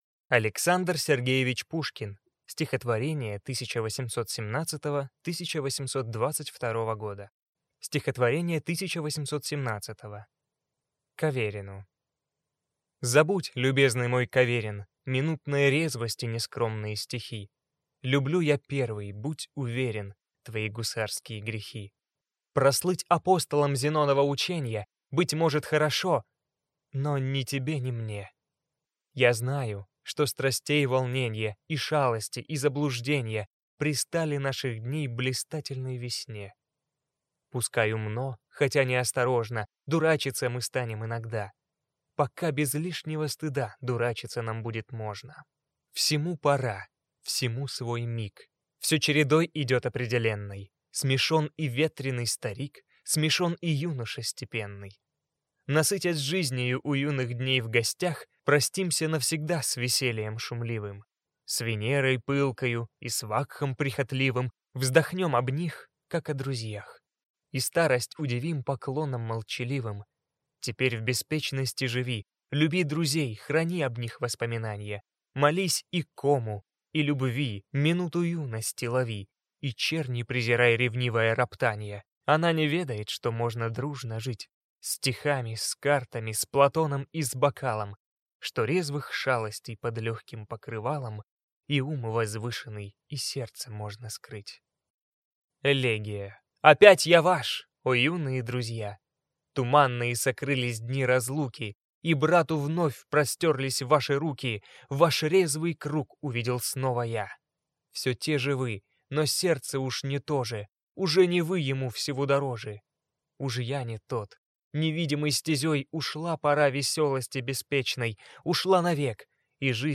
Аудиокнига Стихотворения 1817—1822 | Библиотека аудиокниг